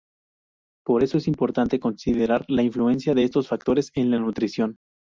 Pronounced as (IPA) /konsideˈɾaɾ/